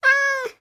Minecraft Version Minecraft Version 1.21.4 Latest Release | Latest Snapshot 1.21.4 / assets / minecraft / sounds / mob / cat / stray / idle1.ogg Compare With Compare With Latest Release | Latest Snapshot